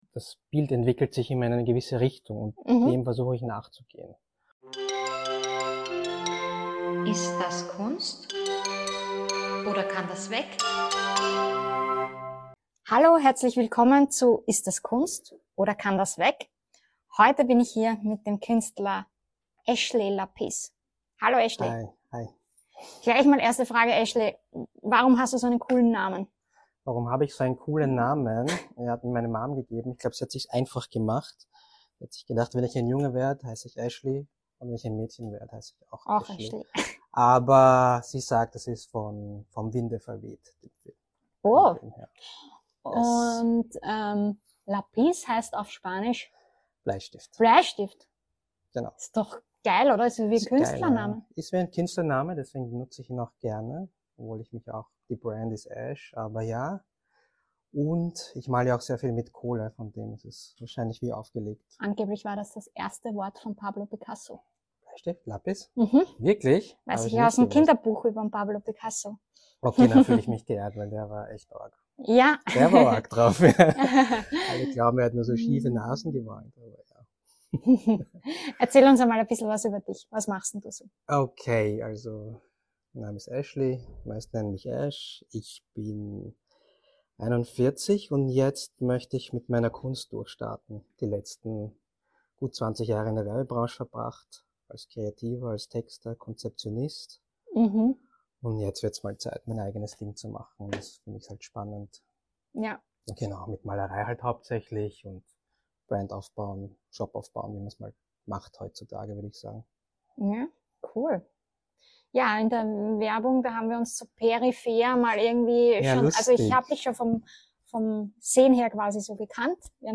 Wir sprechen wir mit dem Streetart-Künstler über seinen Weg von der Spraydose zum eigenen Modelabel. Wie wird aus Kunst auf der Wand eine Marke, die auf Caps, Hoodies und T-Shirts landet?